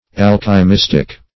Alchymistic \Al`chy*mis"tic\, a.